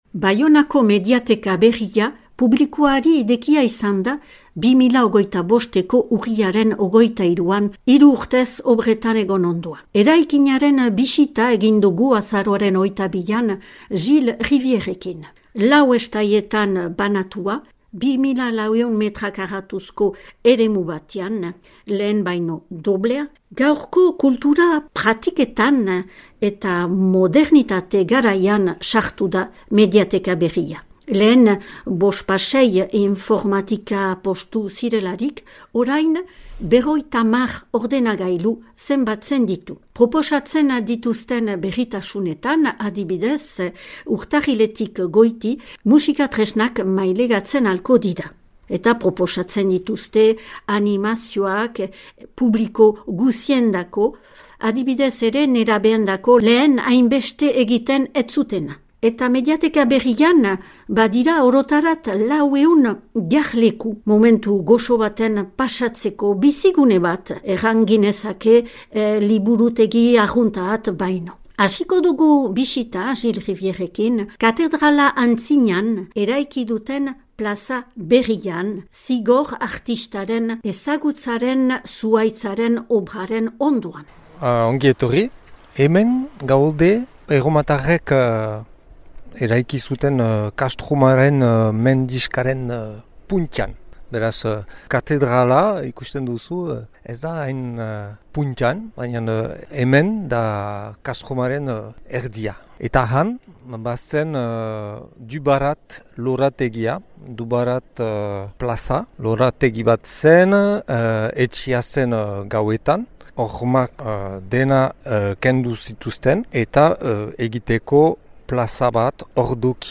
Baionako mediateka berriaren bisita